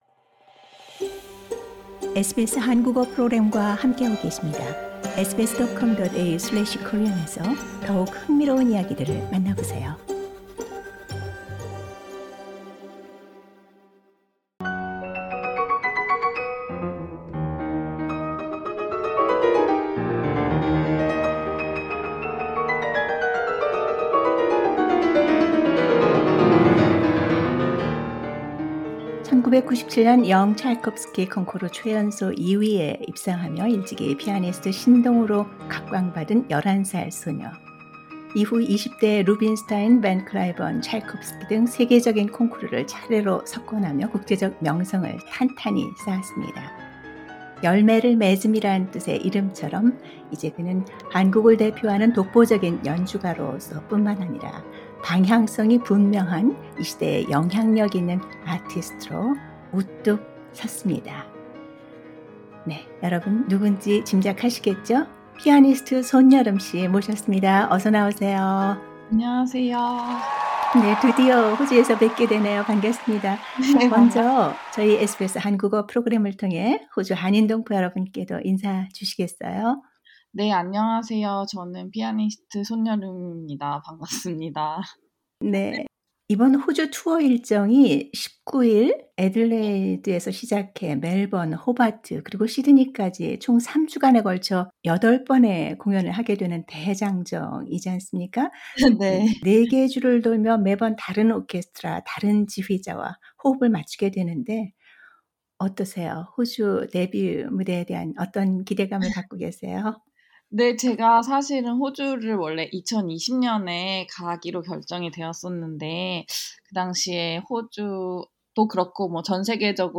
인터뷰 : "화강암의 힘과 시적 감수성의 조화"... 피아니스트 '손열음'